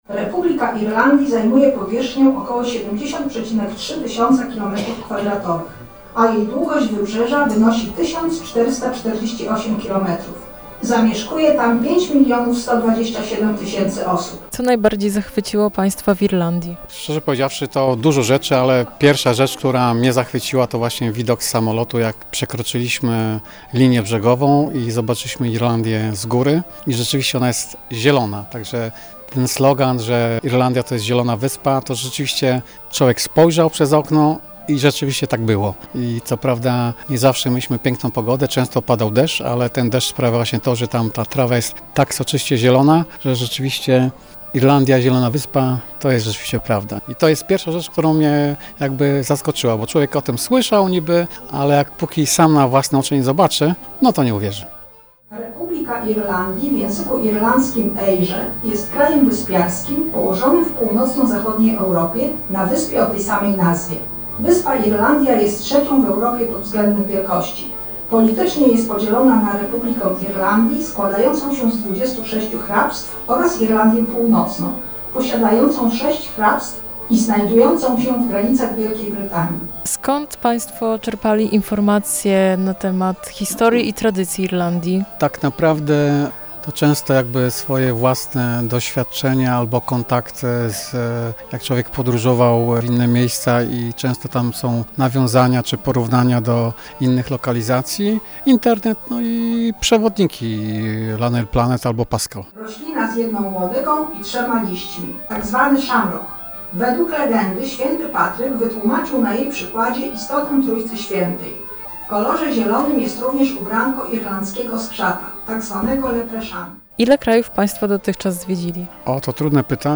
Na miejscu była również nasza reporterka.